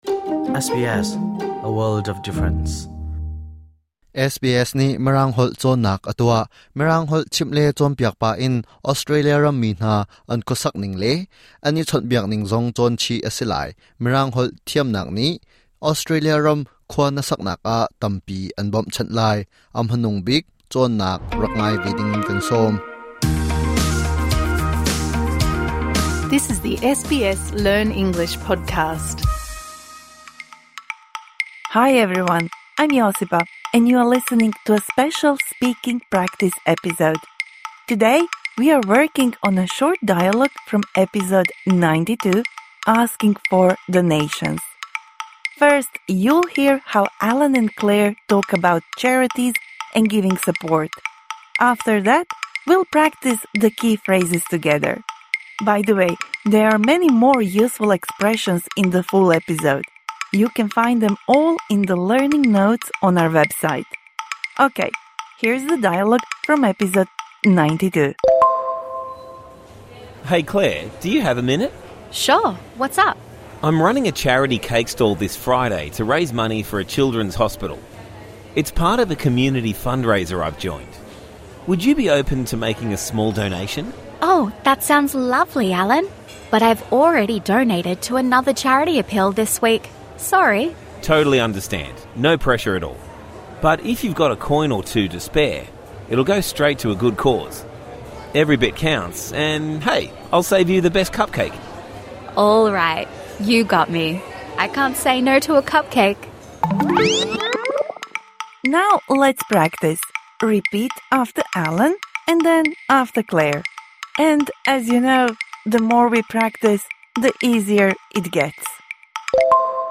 This bonus episode provides interactive speaking practice for the words and phrases you learnt in #92 Asking for donations (Med).